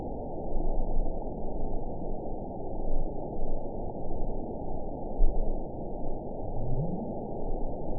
event 912313 date 03/24/22 time 12:49:37 GMT (3 years, 1 month ago) score 9.27 location TSS-AB03 detected by nrw target species NRW annotations +NRW Spectrogram: Frequency (kHz) vs. Time (s) audio not available .wav